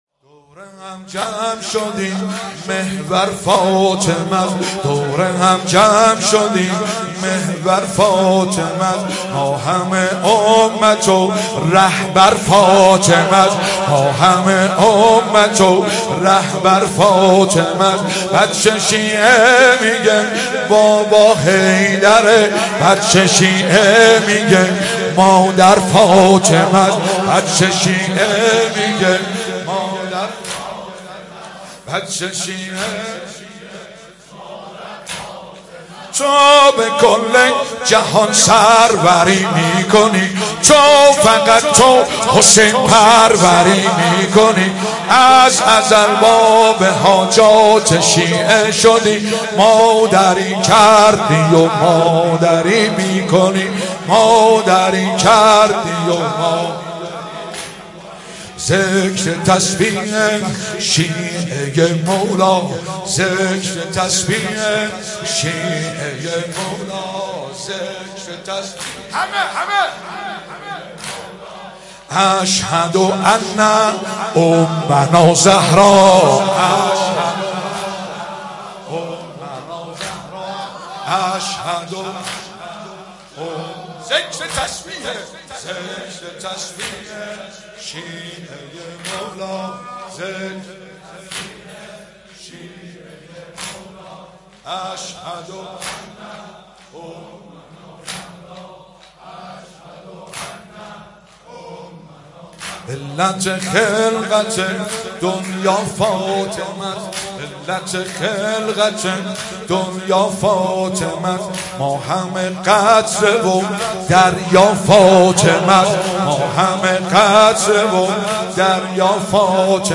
شب اول فاطمیه دوم بهمن96